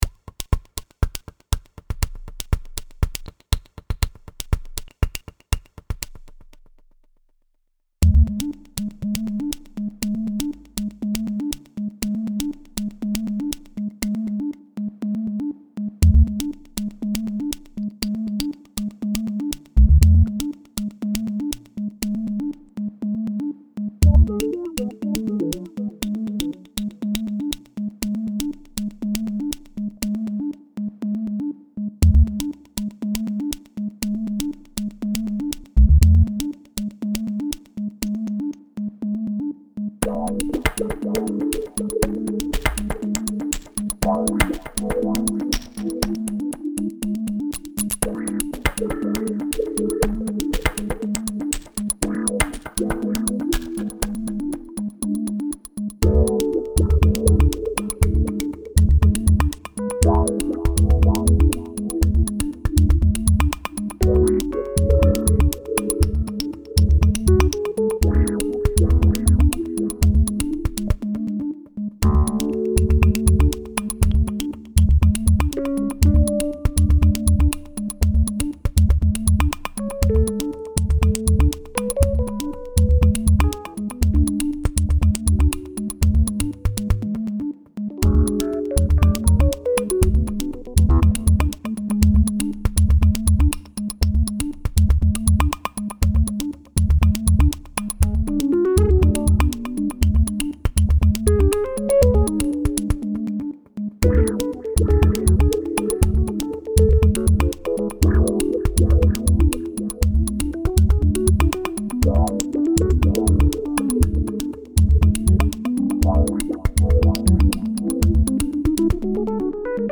Synthie-Motiv